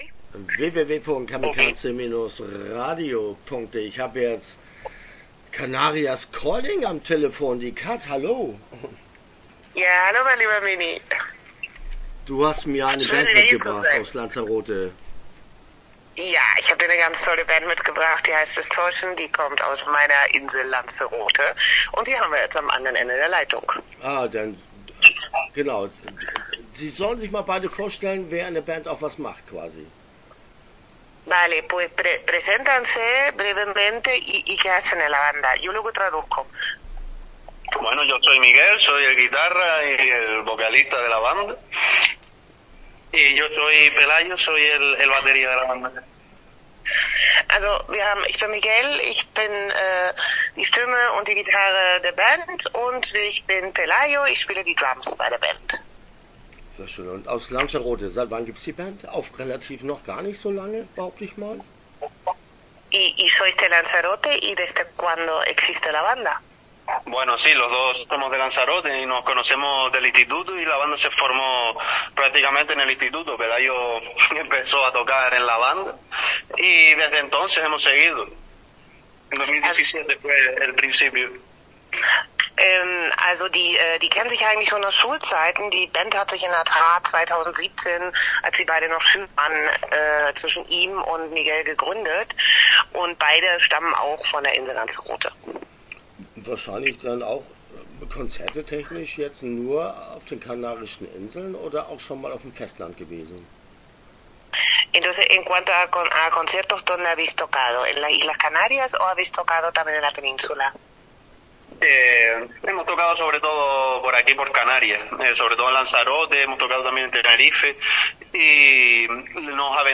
Distortion - Interview Teil 1 (13:17)